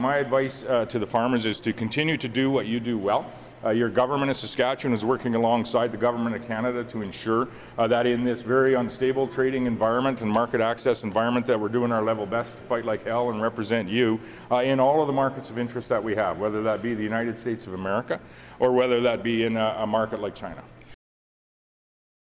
Moe spoke to reporters at the Saskatoon airport prior to departing on a flight to the nation’s capital for additional meetings with Prime Minister Mark Carney and senior cabinet ministers.